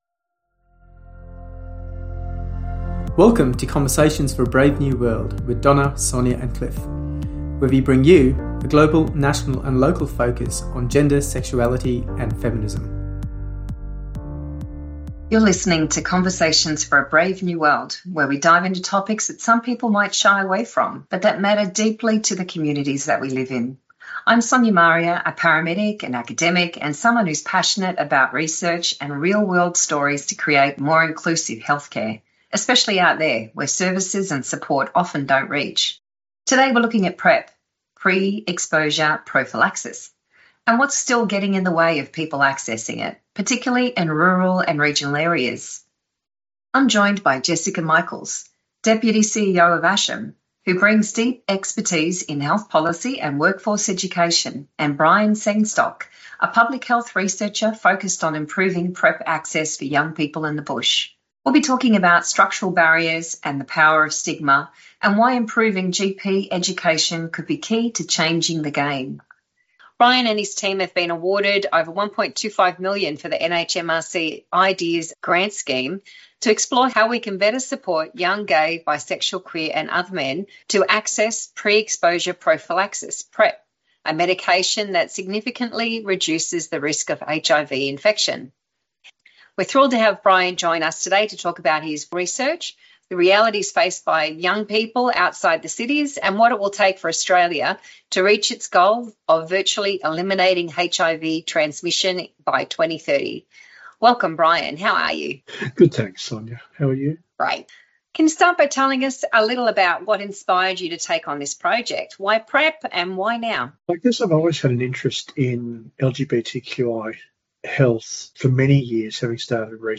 It’s a vital, honest conversation about agency, access, and what it really takes to build health equity—out here, too.